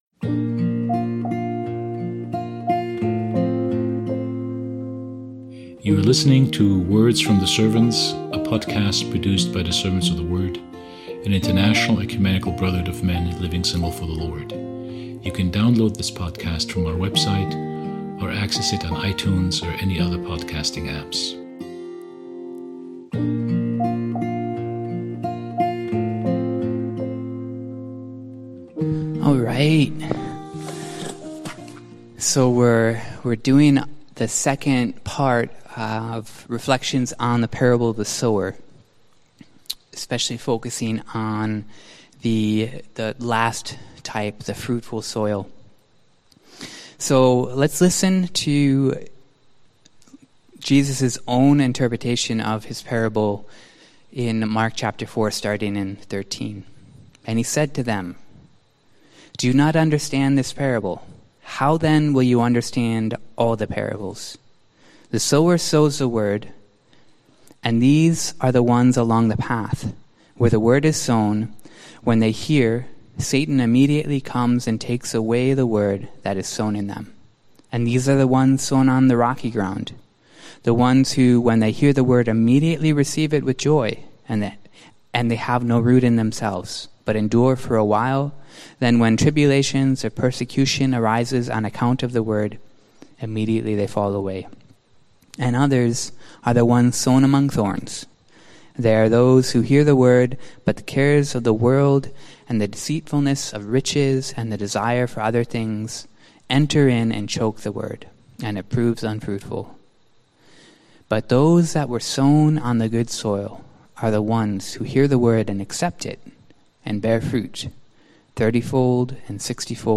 Here’s the second talk which focuses on how a disciple can be fruitful in Christ.